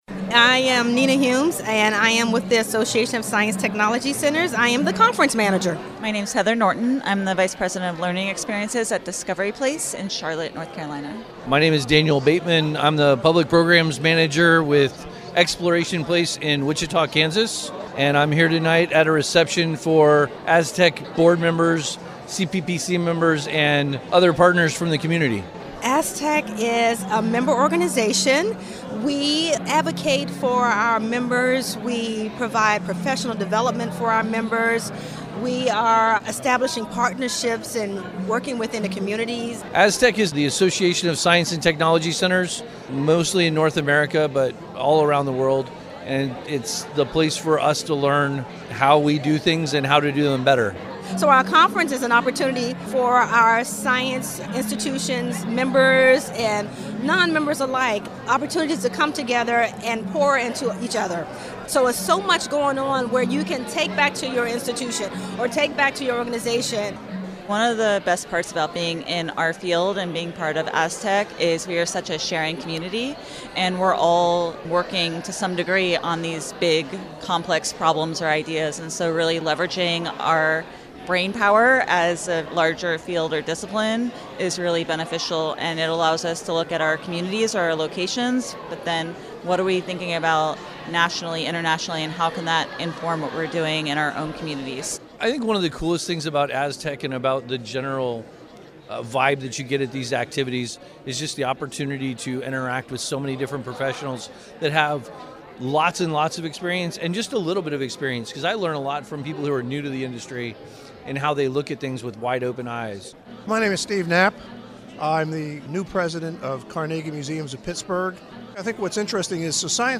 Our microphones were rolling on March 4th, 2020 at the Association of Science and Technology Centers (ASTC) welcome reception at MuseumLab, as they prepare for the 2020 annual conference to be held in Pittsburgh in October. Hear from industry professionals from across the country about their experiences in ASTC, peer collaboration, and how they’re striving to share what their organization has to offer as many people as possible.